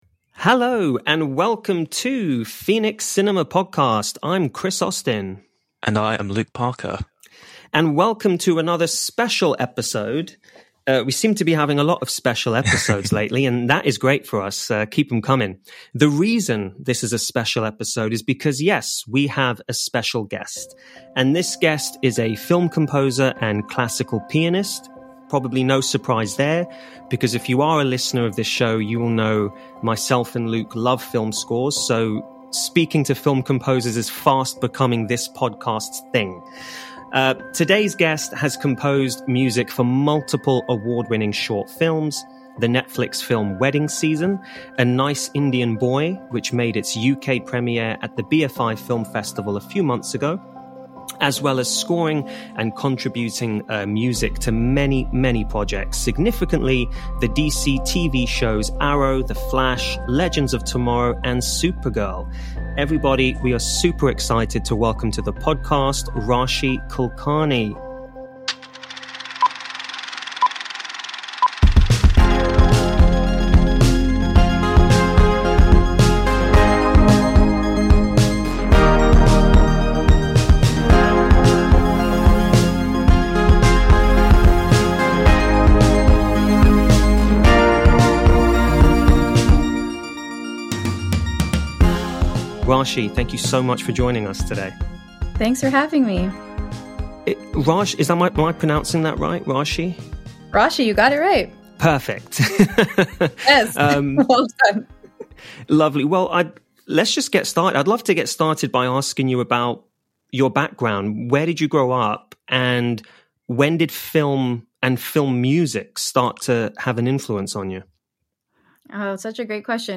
But that's the result of a great conversation.